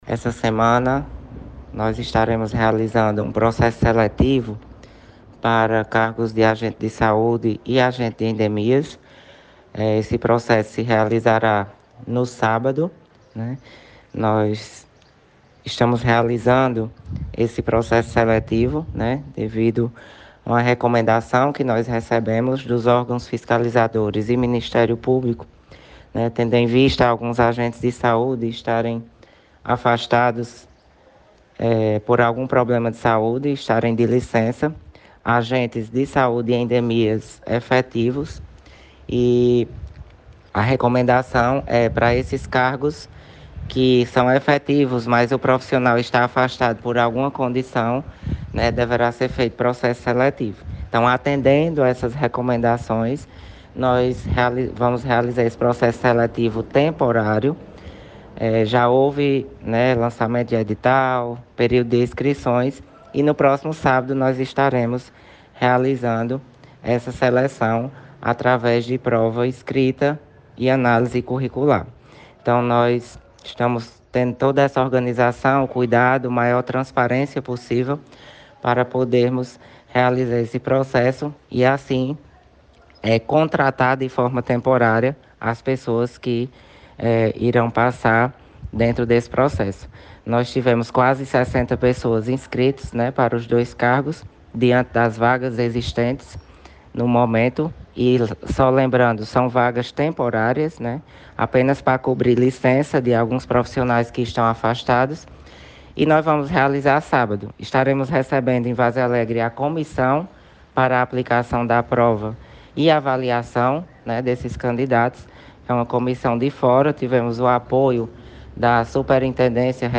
O Secretário Municipal de Saúde, Ivo Leal, anunciou na manhã desta quinta-feira, 15, processo seletivo para agentes de saúde e de endemias. Ivo mandou um áudio para a Rádio Cultura [FM 96.3].